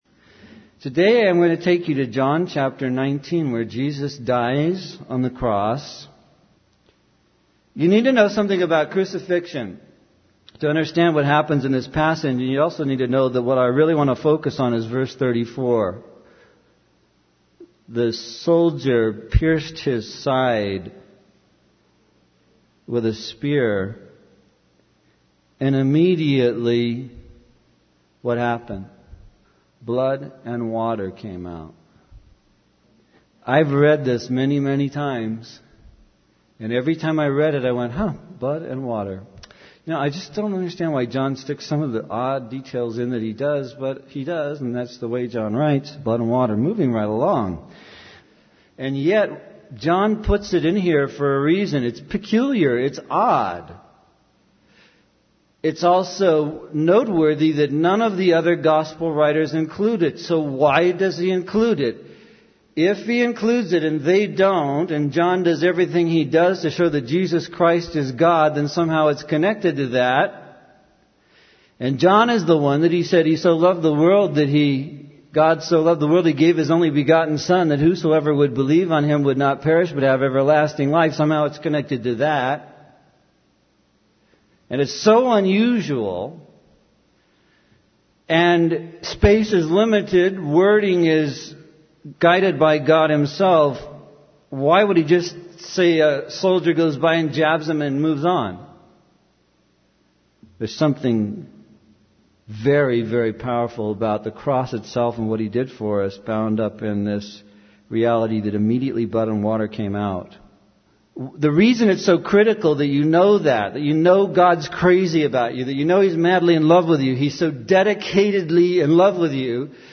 In this sermon, the preacher emphasizes the importance of understanding the love of God through the crucifixion of Jesus Christ. He explains that the blood and water that came out when Jesus was pierced on the cross is a direct reaction to bearing the sins and hell of humanity. The preacher emphasizes that there is no other way to salvation except through the blood of Jesus.